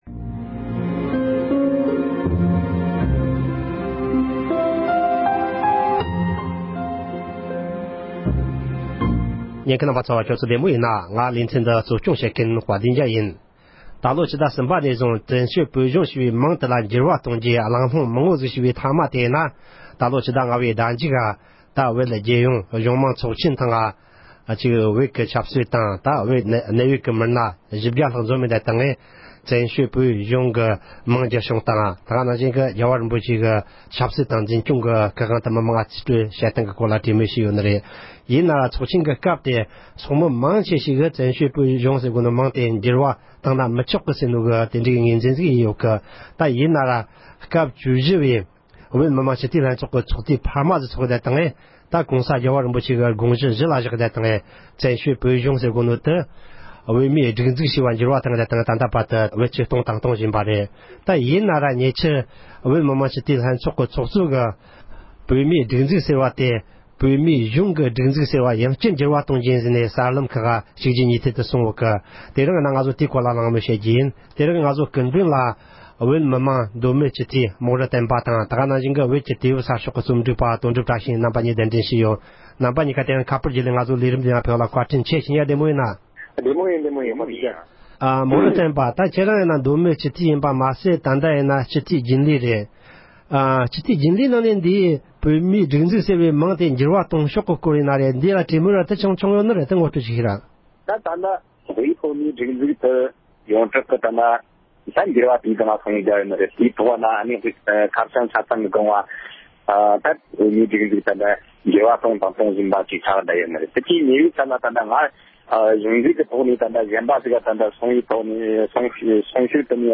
བཙན་བྱོལ་བོད་གཞུང་གི་མིང་བསྒྱུར་ཐད་གླེང་མོལ།